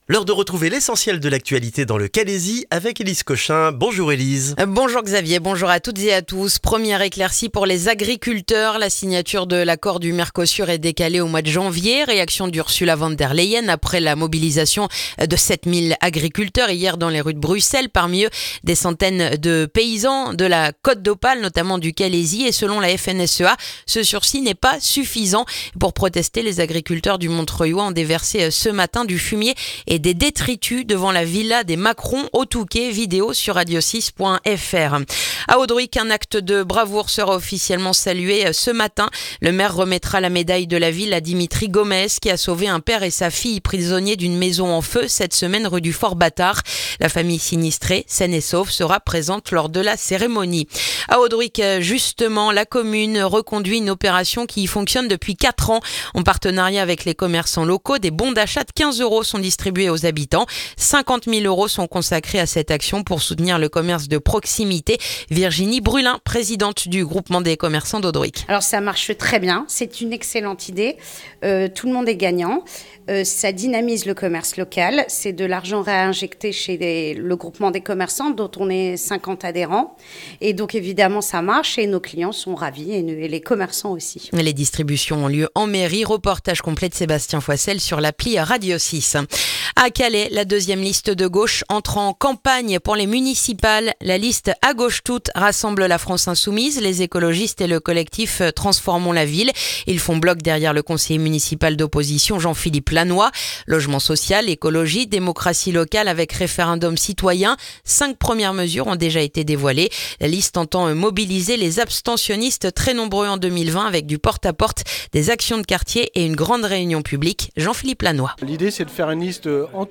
Le journal du vendredi 19 décembre dans le calaisis